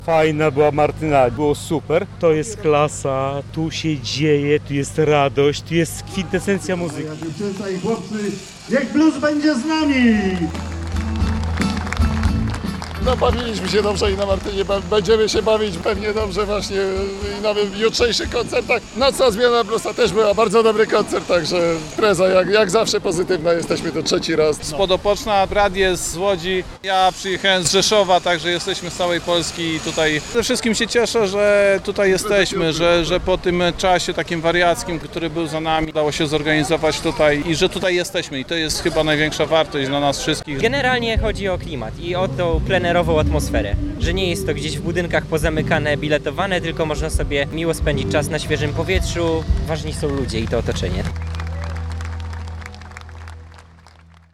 Plenerowe studio Radia Białystok stanęło nieopodal sceny na Placu Marii Konopnickiej